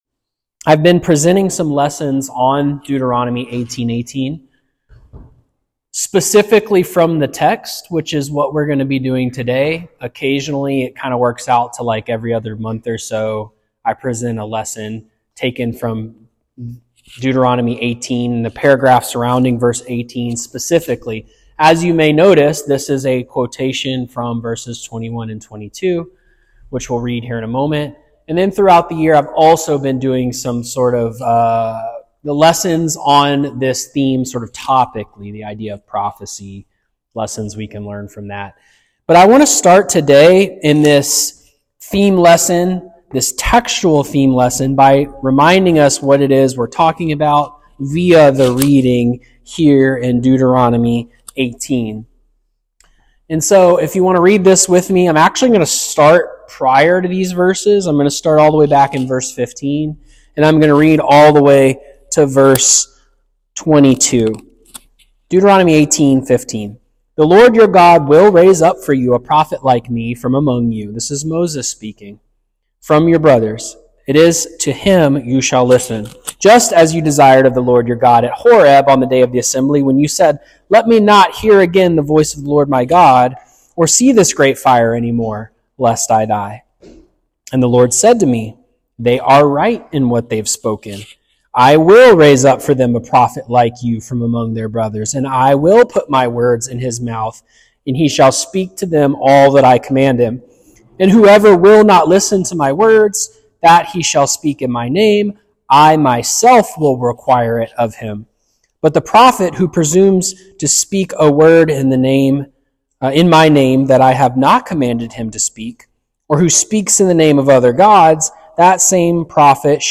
A lesson from our 2025 Annual theme sermon series that explores the meaning, fulfillment, and application of Deuteronomy 18.18.